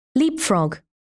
영국